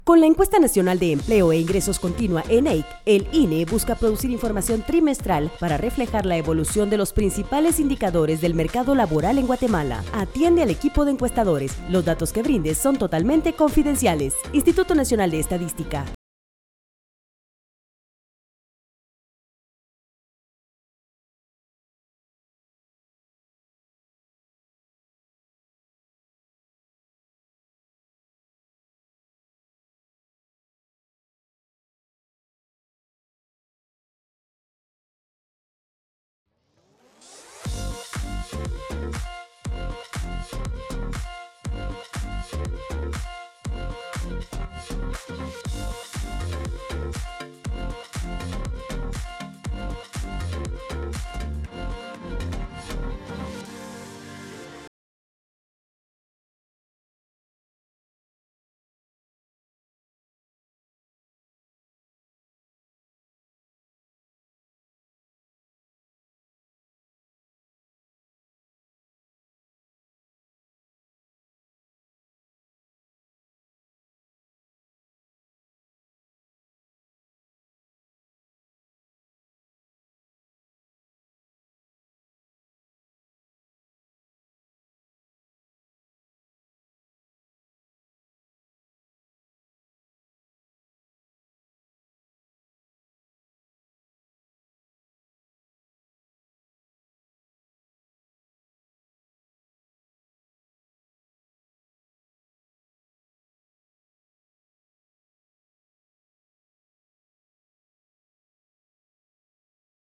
Spot de radio #3